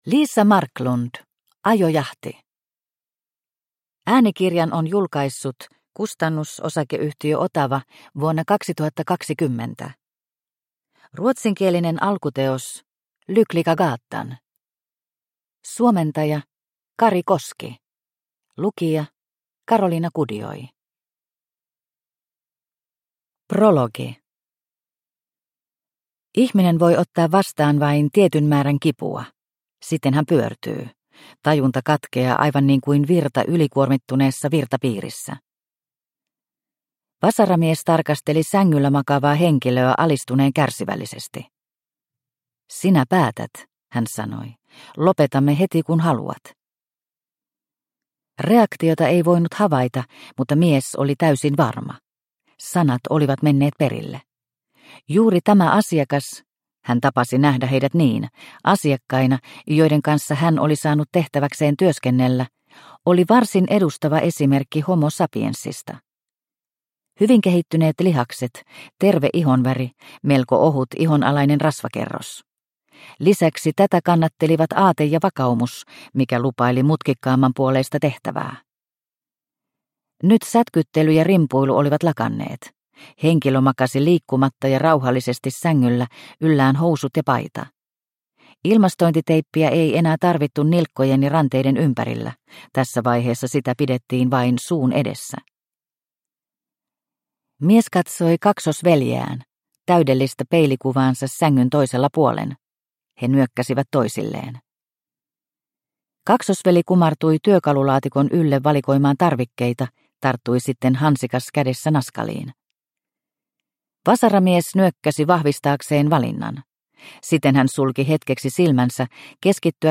Ajojahti – Ljudbok – Laddas ner
Uppläsare: